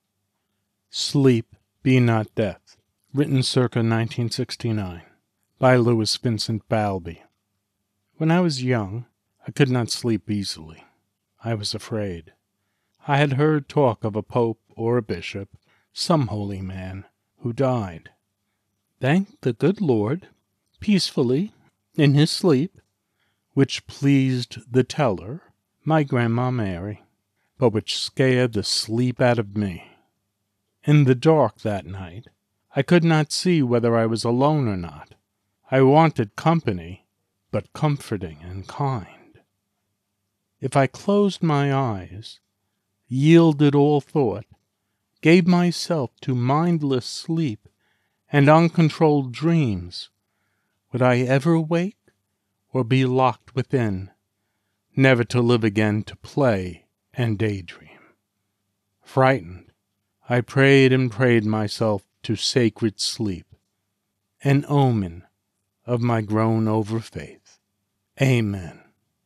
Sleep Be Not Death Poem